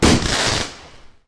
firework